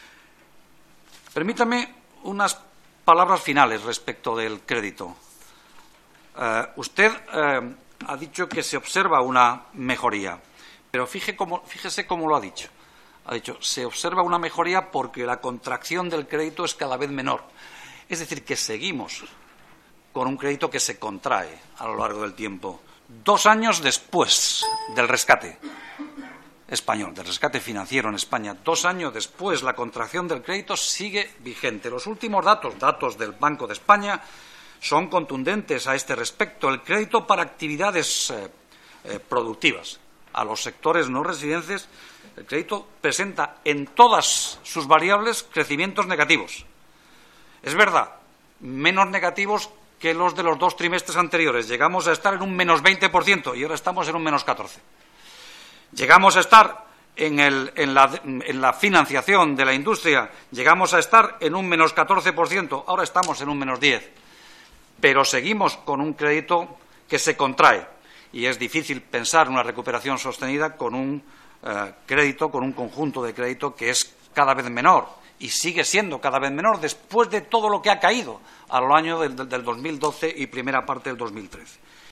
Comisión de Economía. Comparecencia del Gobernador del Banco de España. Fragmento de la intervención de Valeriano Gómez. 17/04/2014